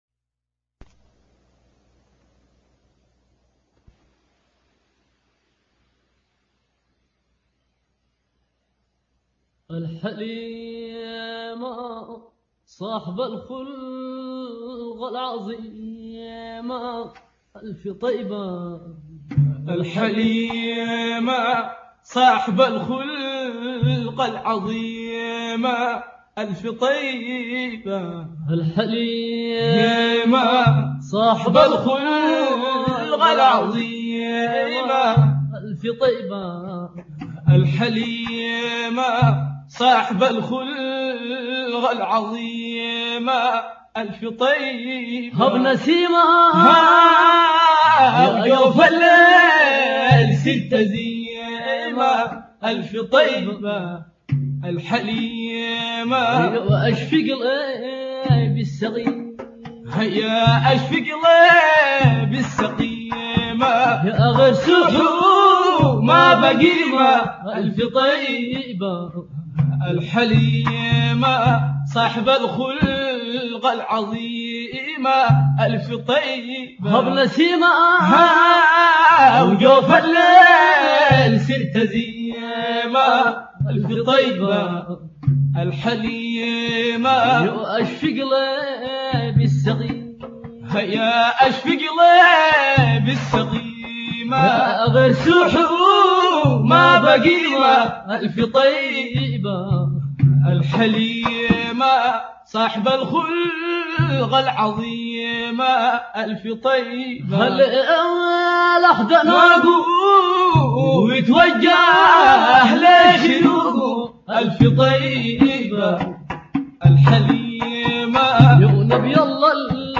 مدحة
بصوت الأبناء والأحفاد